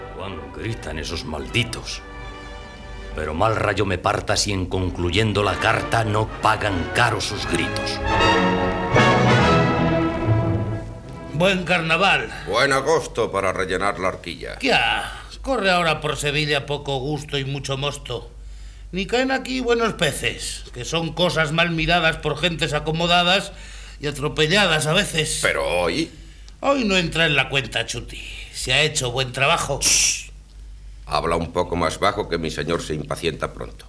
Diàleg d'una escena de l'obra.
Ficció